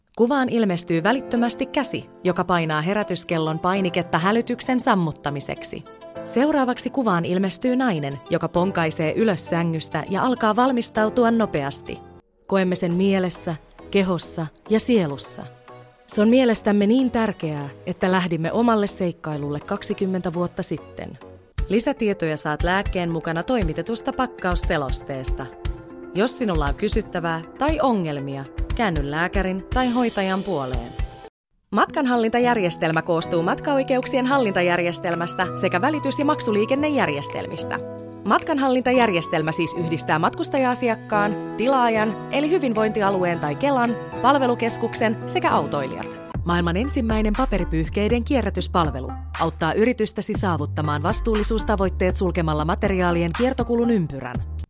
Yritysvideot